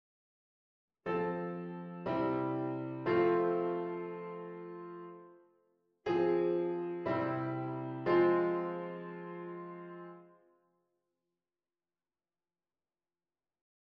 c. 1^ 2^ 3^ dit is eerder een begin dan een afsluiting! de toon die de eerste twee akkoorden gemeenschappelijk hebben, blijft niet liggen.
hier kunnen de gemeenschappelijke tonen wel blijven liggen.